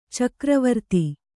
♪ cakra varti